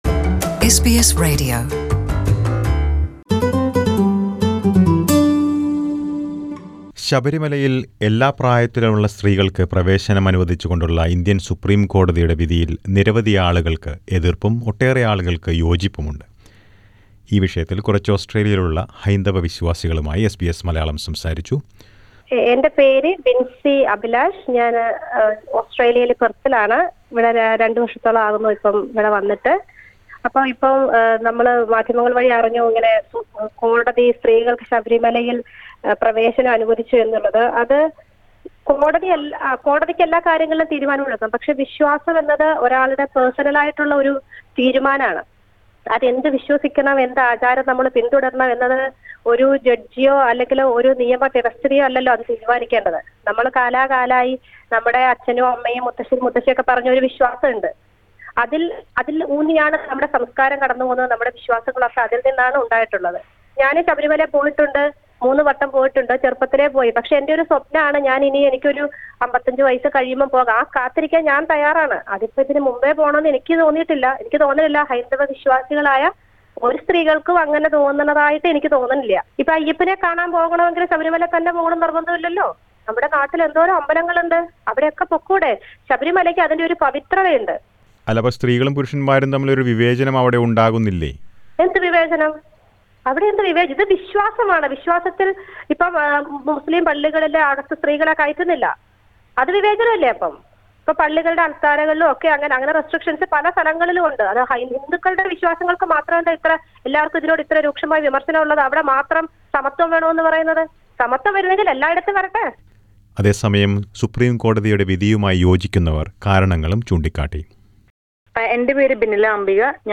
The Indian Supreme Court verdict allowing entry for all women belonging to all age groups to Sabarimala Lord Ayyappa temple in Kerala is viewed with criticism by many while a lot of people have welcomed this verdict. SBS Malayalam speaks to a few devotees from different parts of Australia about this verdict.